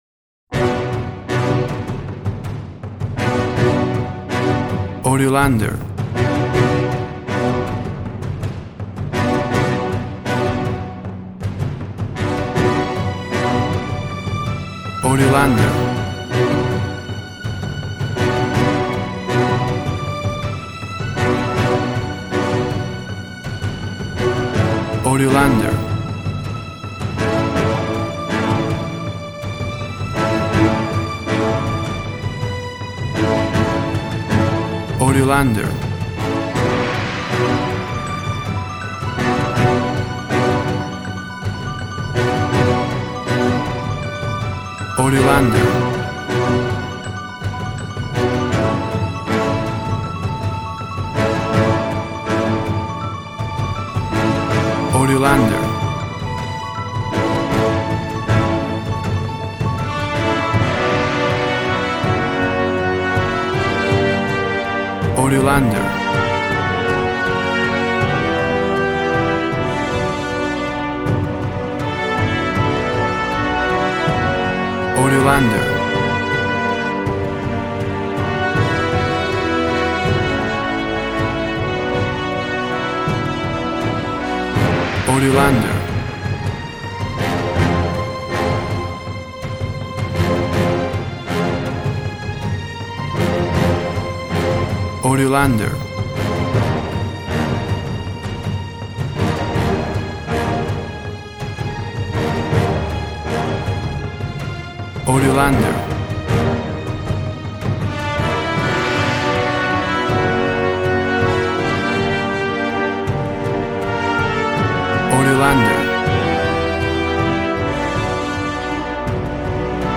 Action and Fantasy music for an epic dramatic world!
Tempo (BPM) 80